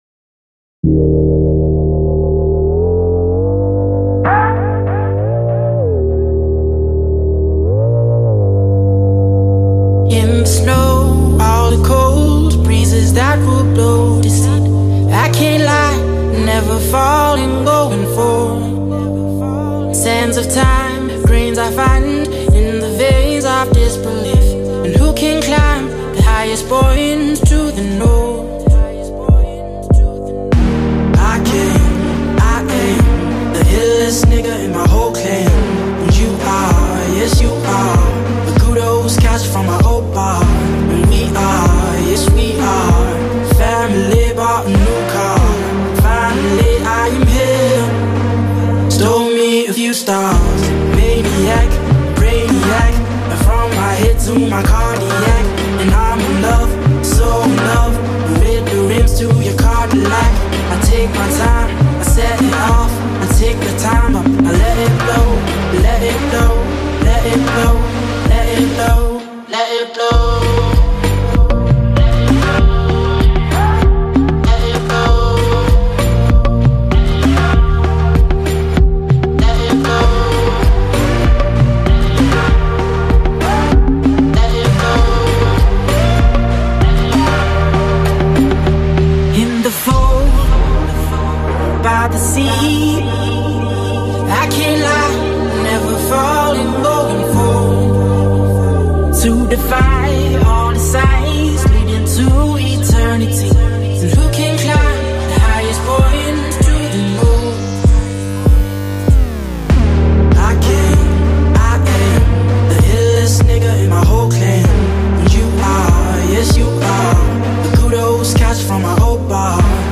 Genres: Electro, Funk, Pop